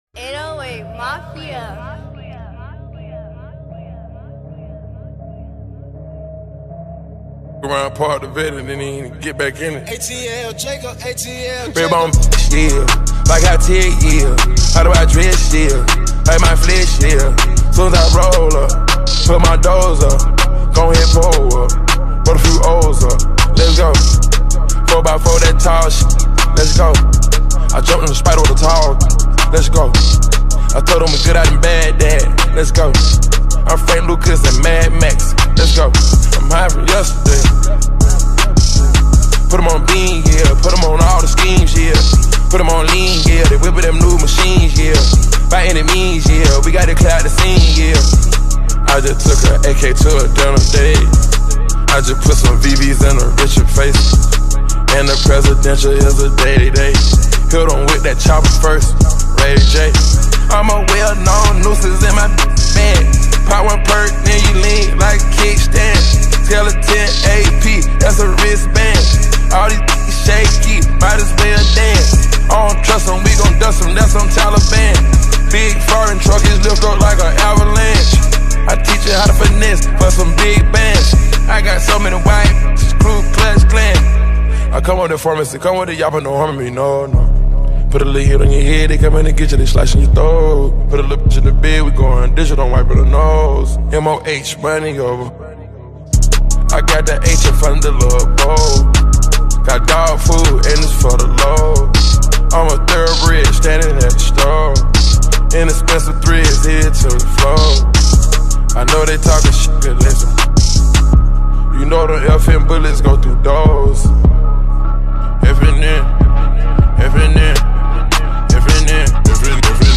GenreRap